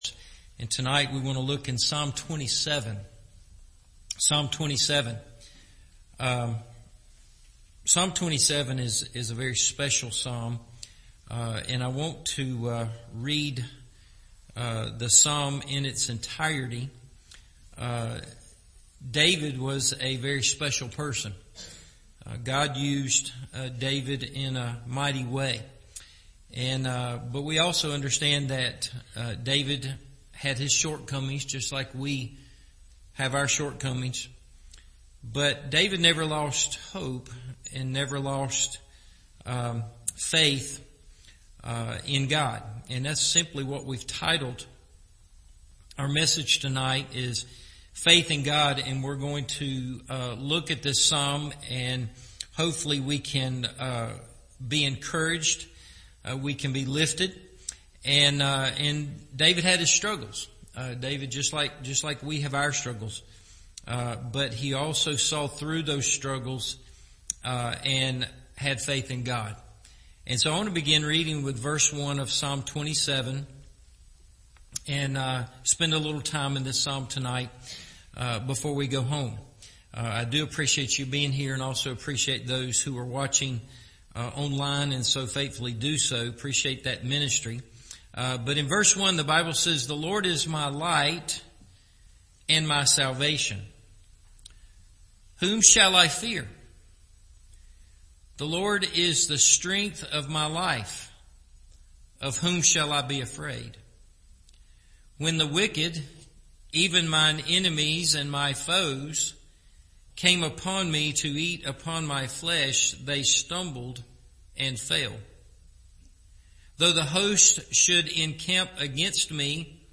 Faith In God – Evening Service